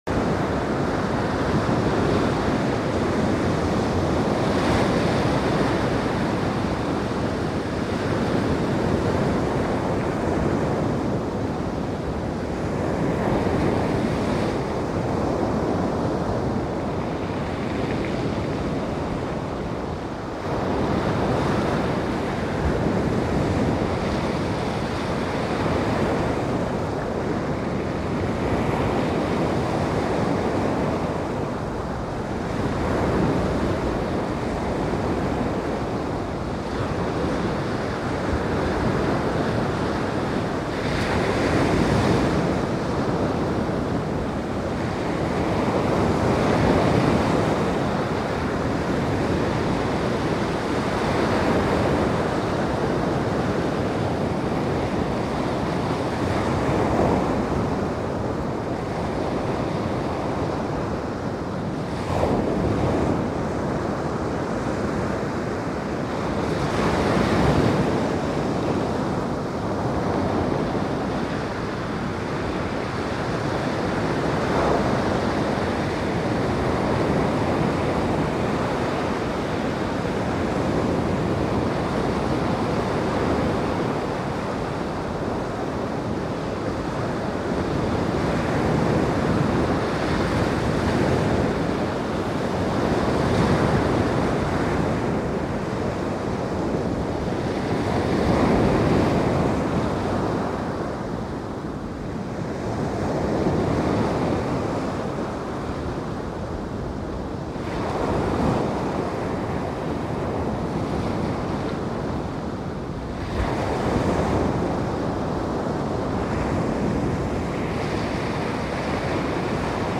Sleep to Ocean Waves Sounds sound effects free download